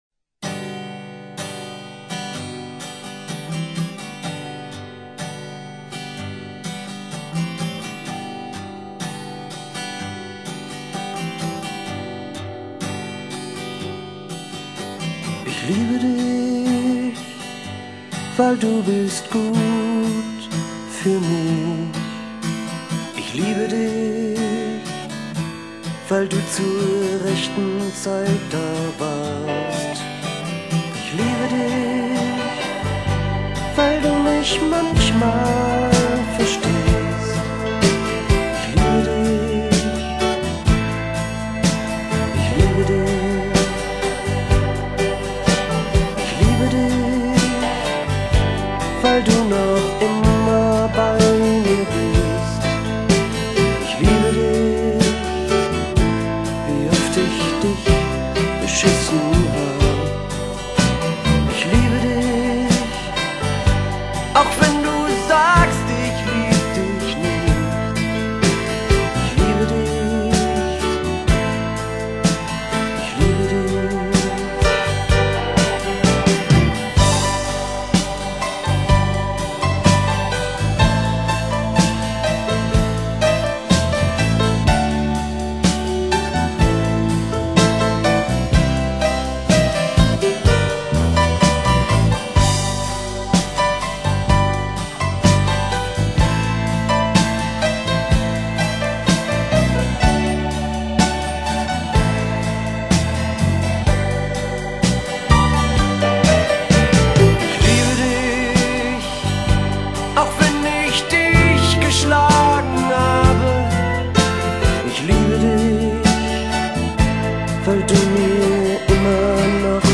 在吉他那悠扬洞开丝绸般黑夜的稠密， 听着这个老男人的歌声